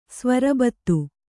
♪ svarabattu